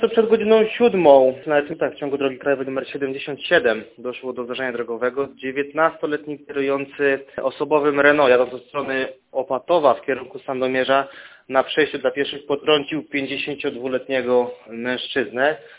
Mówi młodszy aspirant